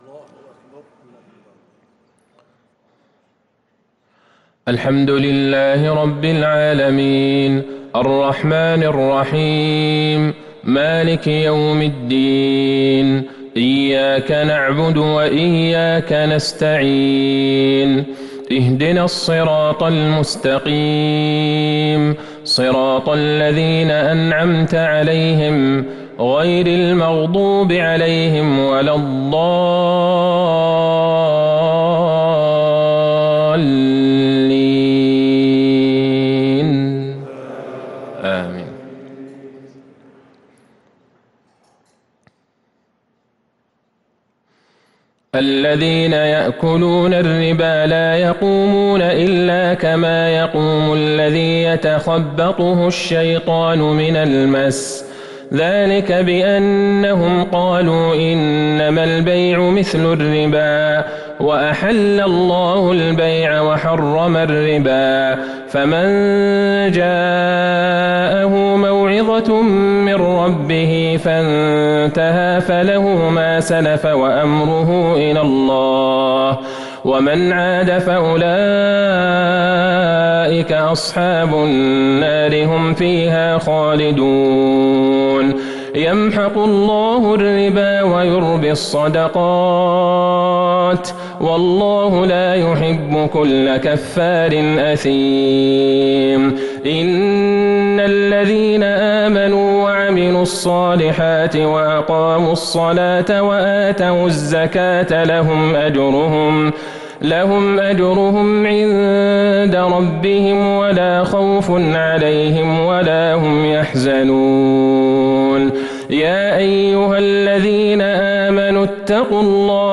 عشاء الأحد ٢٩ جمادى الأولى ١٤٤٣هـ | سورة البقرة ٢٧٥ - ٢٨٣ | Isha prayer from Surah Al-Bakarah 2-1-2022 > 1443 🕌 > الفروض - تلاوات الحرمين